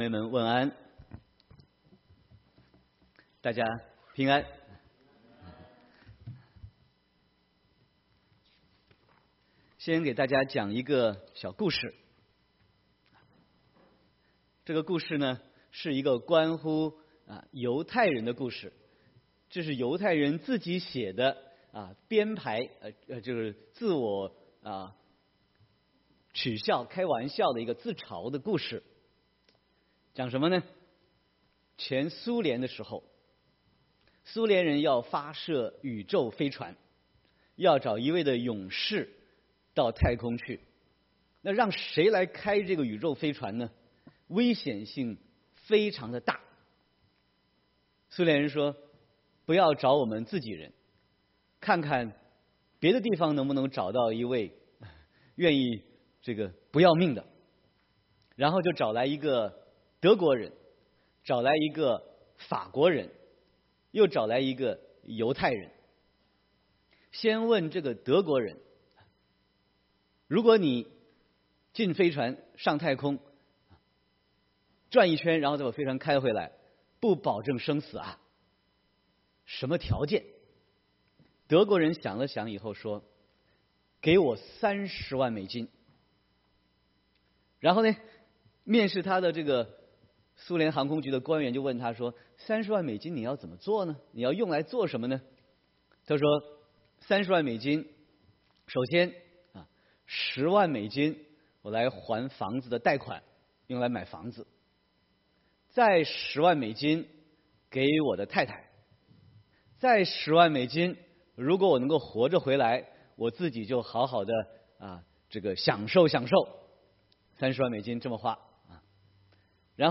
Sermon 5/6/2018